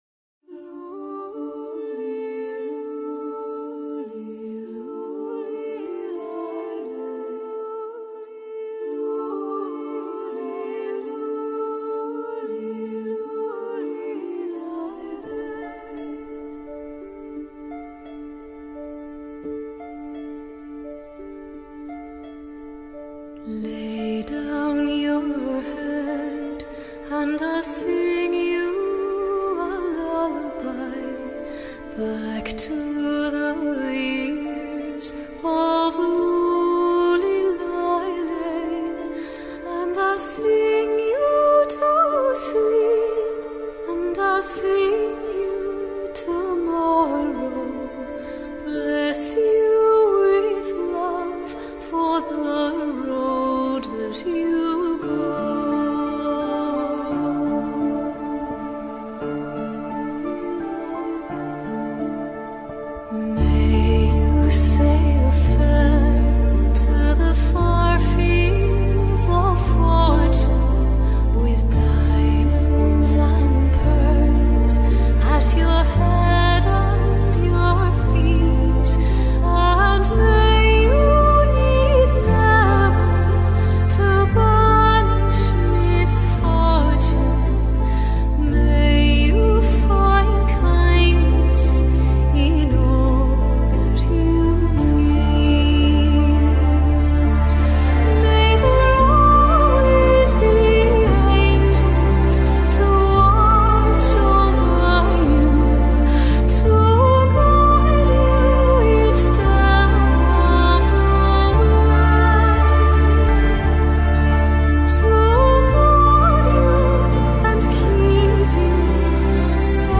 KFbw70KW5Jq_Musica-para-relajarse.mp3